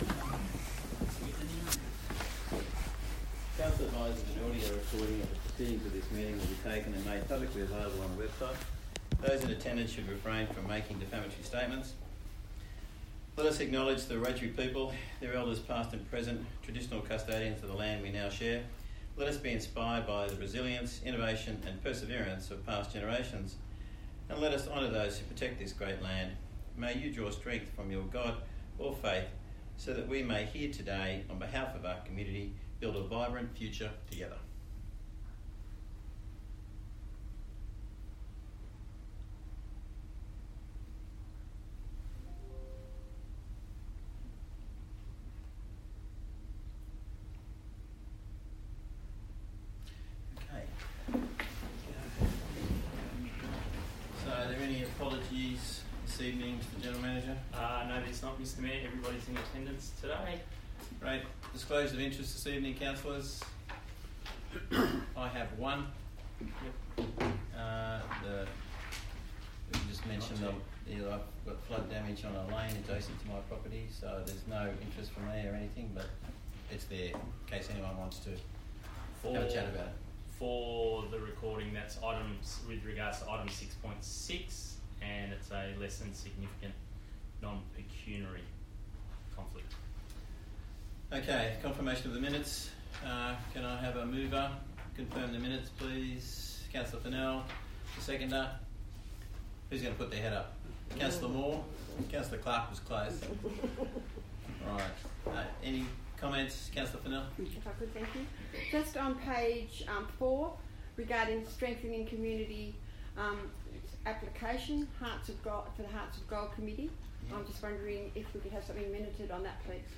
16 July 2024 Ordinary Meeting